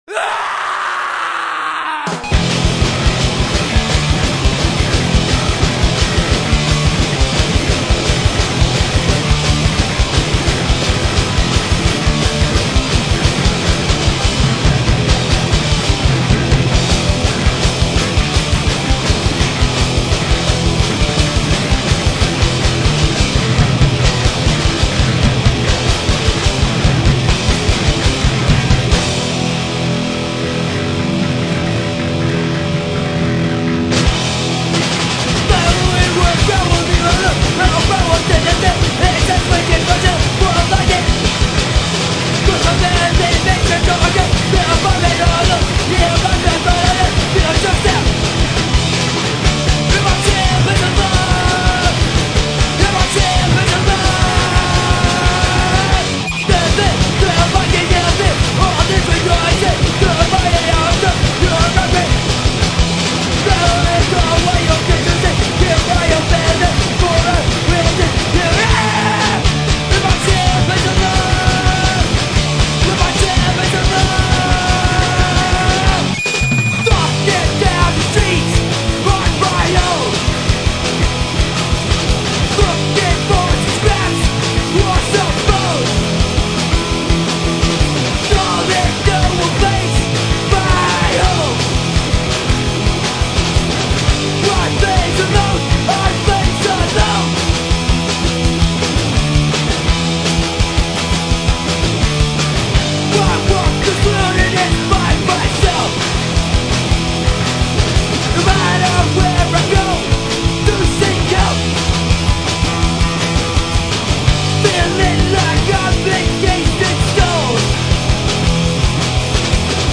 Ugly blasts of 1982 hardcore punk from these Florida kids.